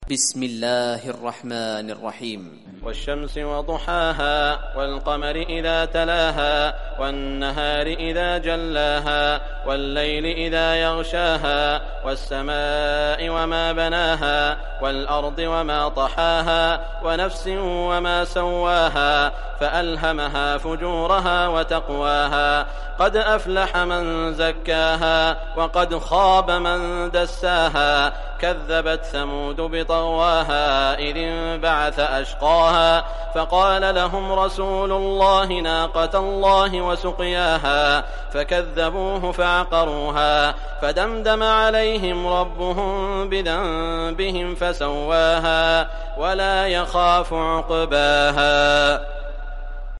Surah Ash-Shams Recitation by Sheikh Shuraim
Surah Ash-Shams, listen or play online mp3 tilawat / recitation in Arabic in the beautiful voice of Sheikh Saud al Shuraim.